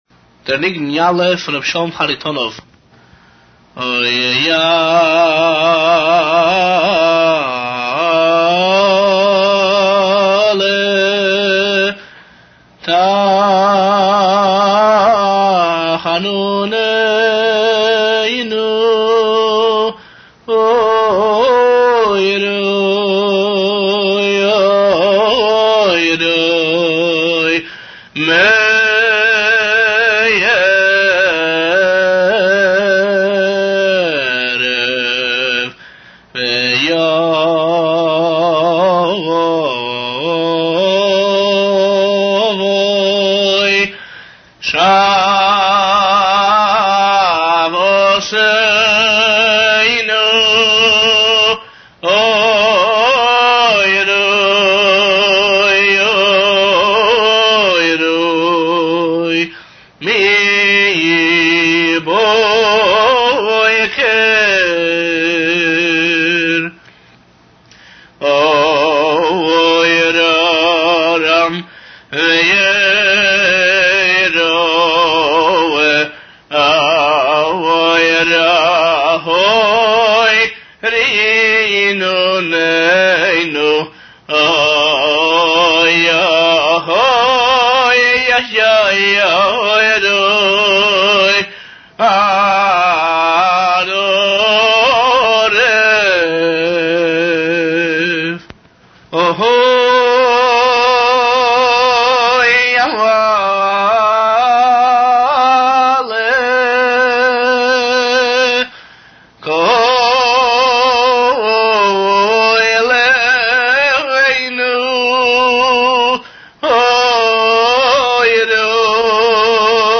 סגנון הניגון הוא ניגון התוועדות, רציני, עמוק ומלא רגש.